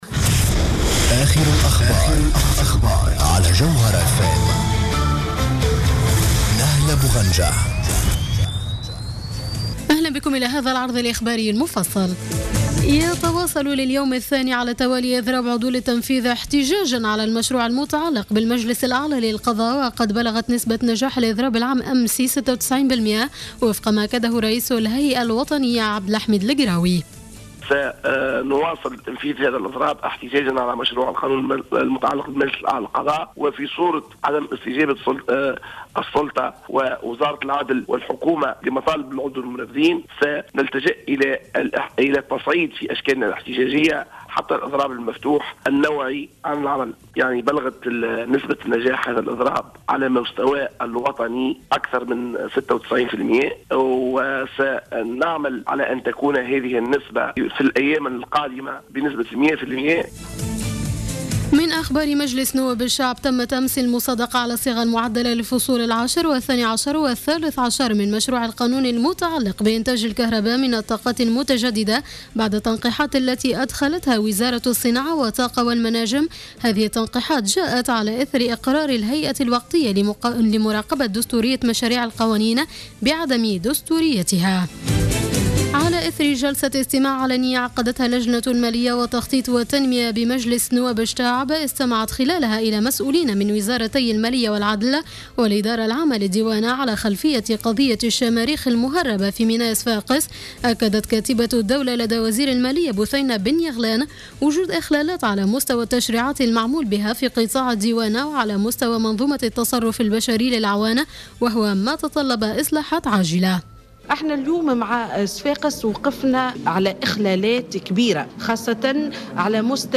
نشرة أخبار منتصف الليل ليوم الخميس 16 أفريل 2015